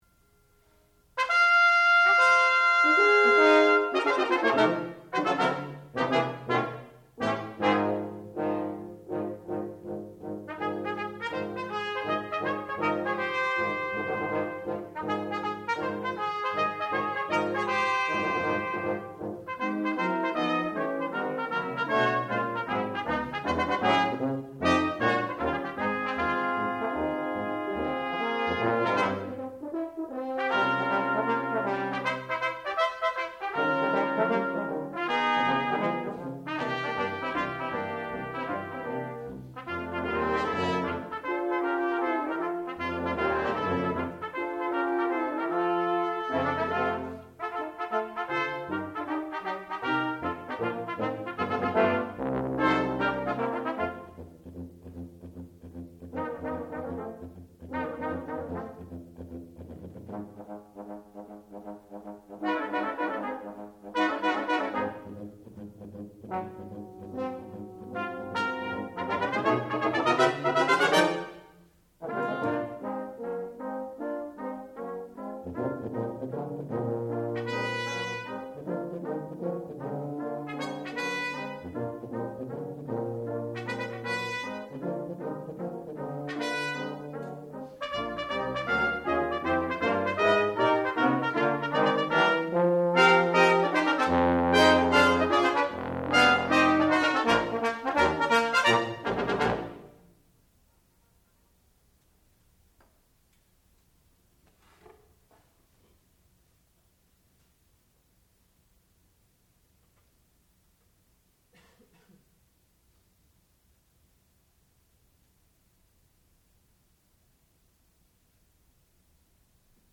sound recording-musical
classical music
trumpet
tuba